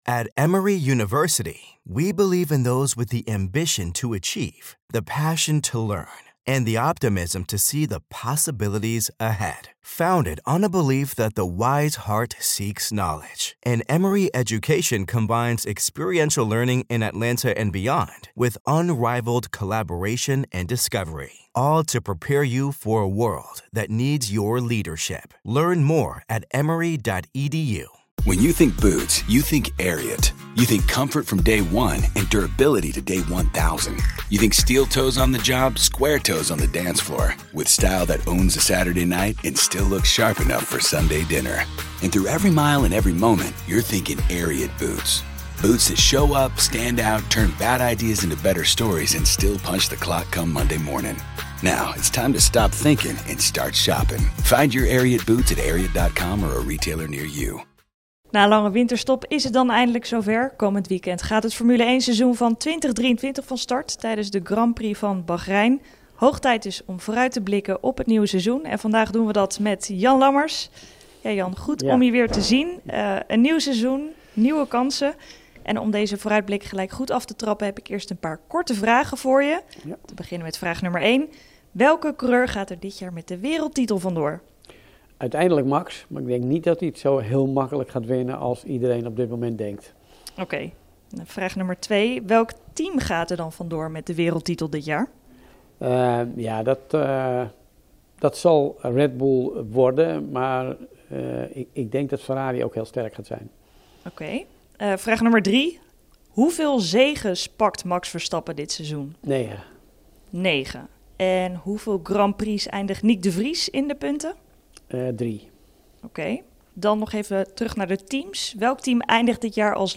Na een lange winterstop is het eindelijk zover: komend weekend gaat het Formule 1-seizoen van 2023 van start, tijdens de Grand Prix van Bahrein. In gesprek met voormalig F1-coureur Jan Lammers blikken we uitgebreid vooruit op het nieuwe seizoen.